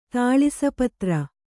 ♪ tāḷisa patra